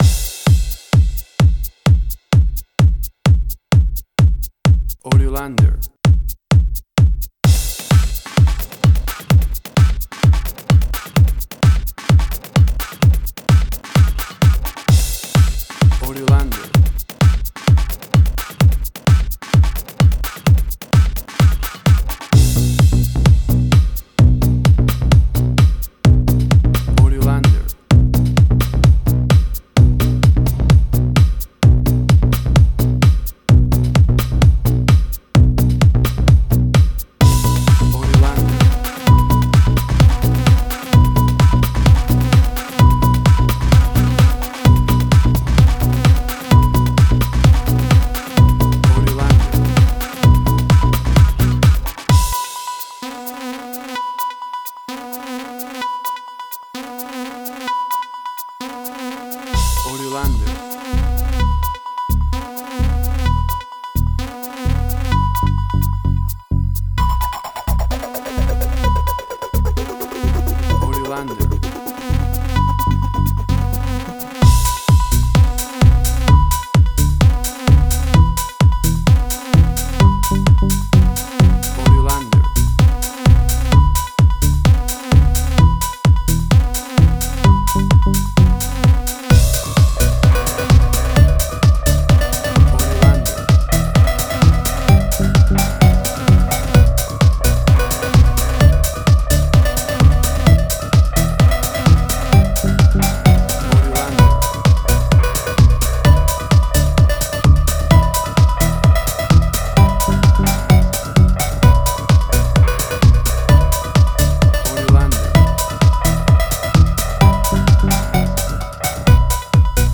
House.
Tempo (BPM): 130